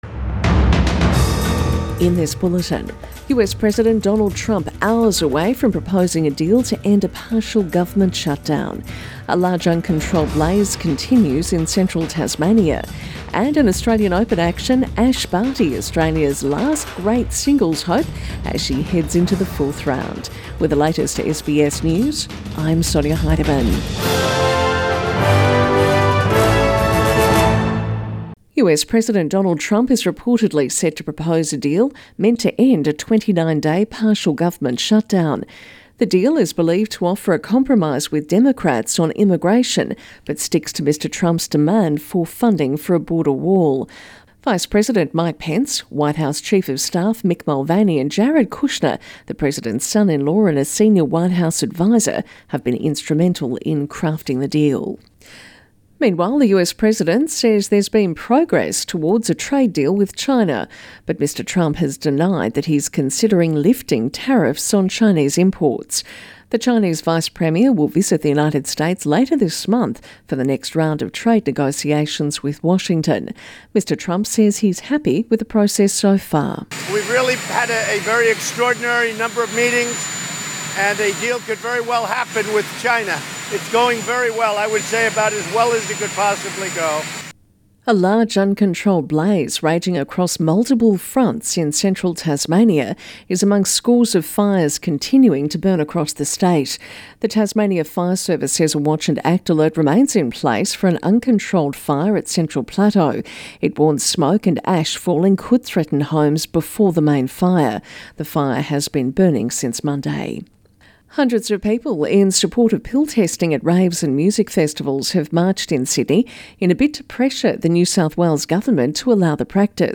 AM Bulletin 20 January